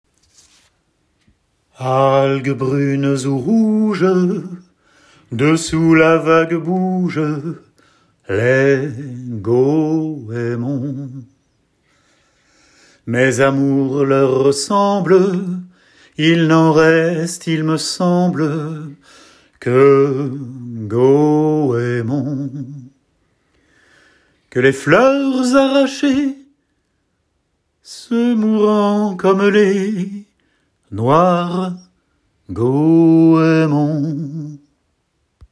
Poète du XIXe siècle (anglais avec accent français)